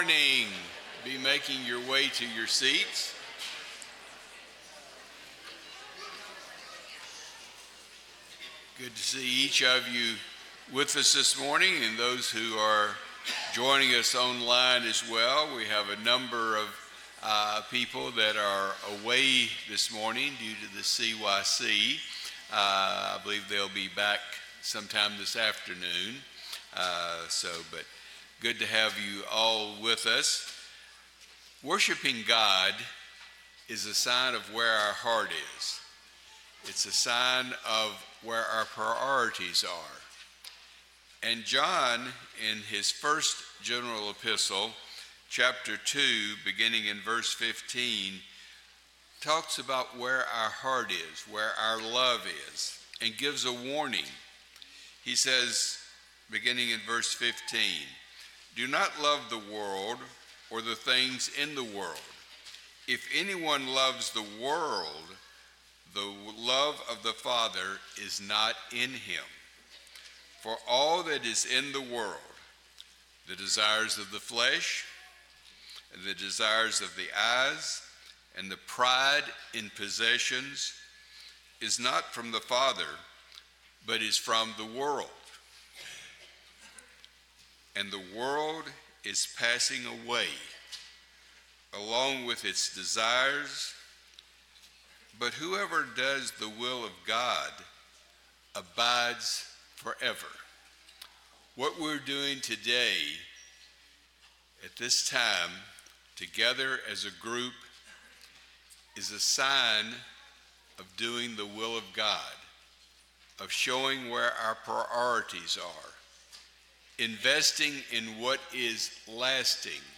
Psalm 107:6, English Standard Version Series: Sunday AM Service